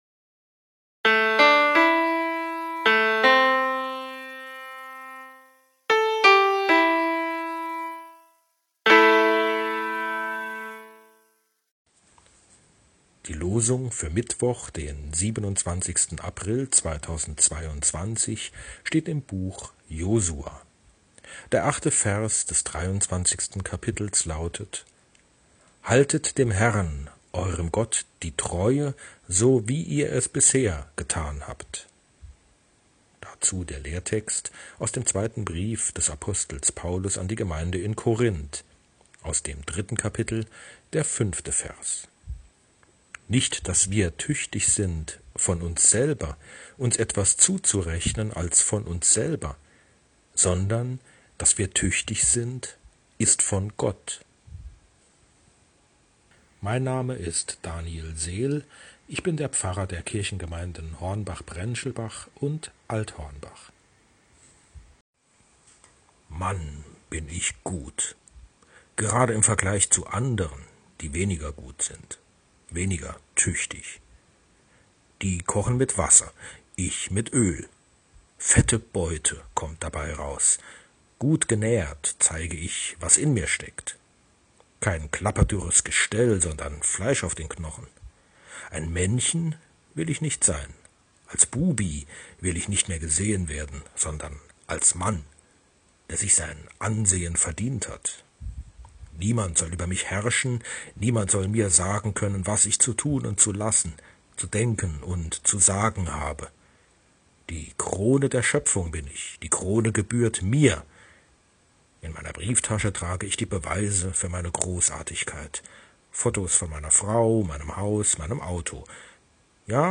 Losungsandacht für Mittwoch, 27.04.2022